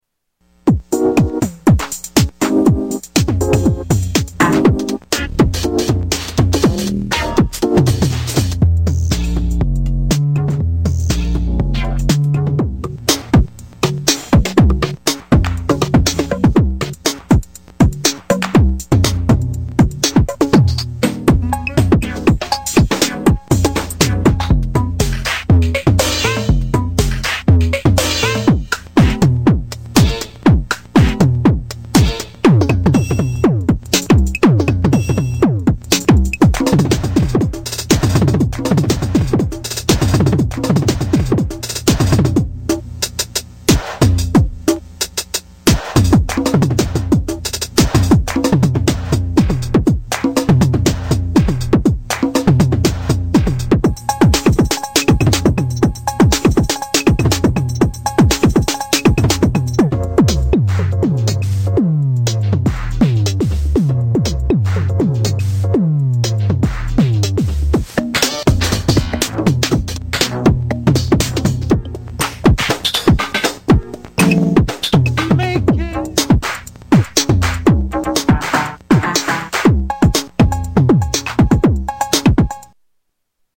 Jomox XBase888 Drum Machine
Category: Sound FX   Right: Personal